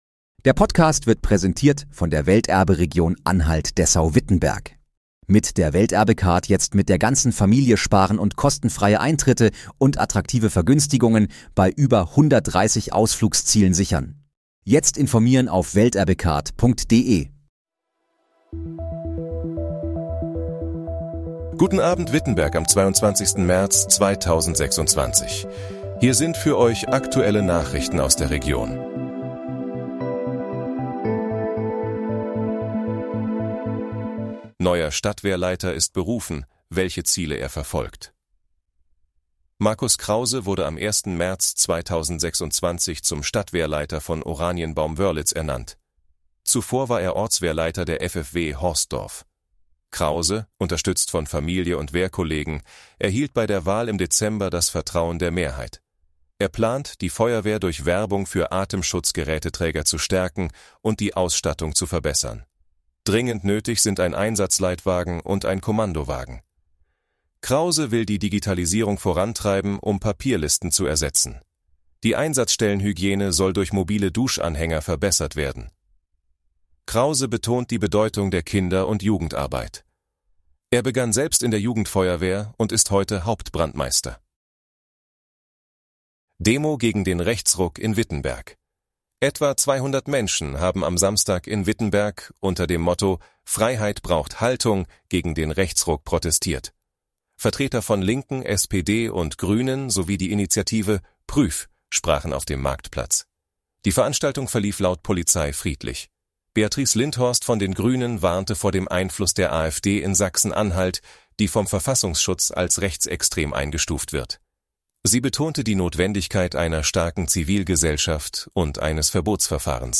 Guten Abend, Wittenberg: Aktuelle Nachrichten vom 22.03.2026, erstellt mit KI-Unterstützung
Nachrichten